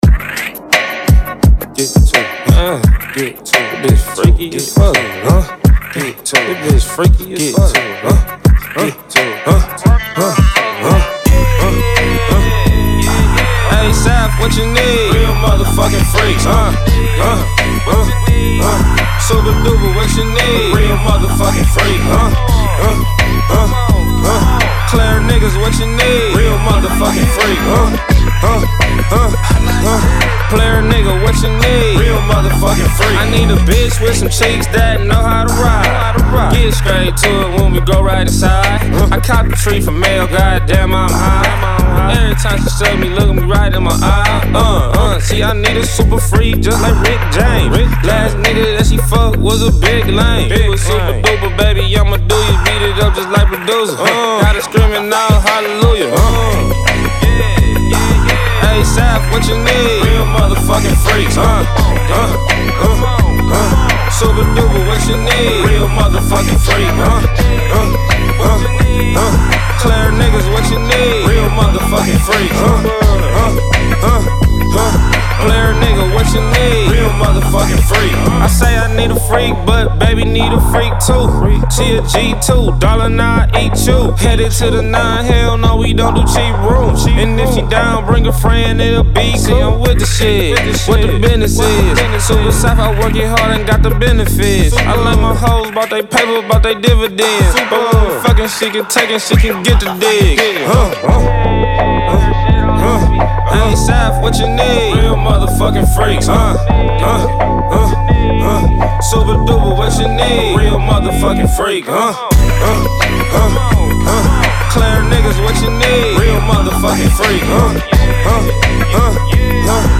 Hiphop
Hip Hip Old School Sample